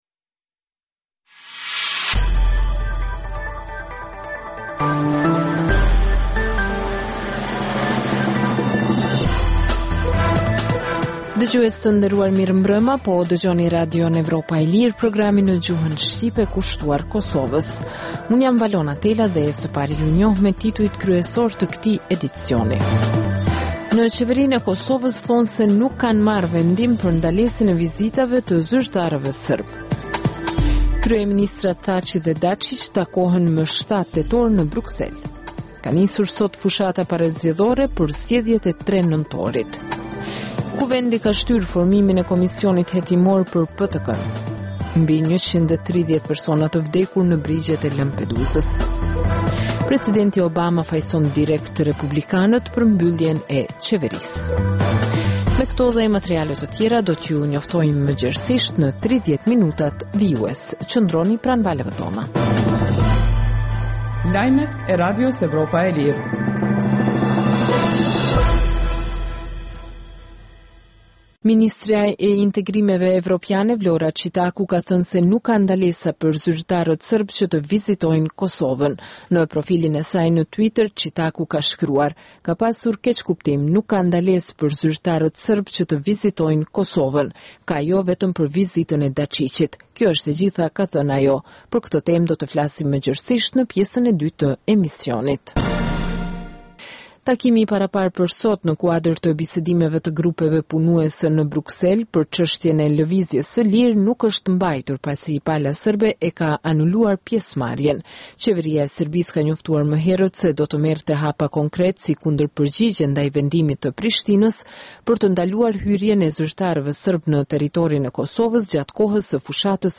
Emisioni i orës 21:00 është rrumbullaksim i zhvillimeve ditore në Kosovë, rajon dhe botë. Rëndom fillon me buletinin e lajmeve dhe vazhdin me kronikat për zhvillimet kryesore politike të ditës. Në këtë edicion sjellim intervista me analistë vendor dhe ndërkombëtar për zhvillimet në Kosovë, por edhe kronika dhe tema aktuale dhe pasqyren e shtypit ndërkombëtar.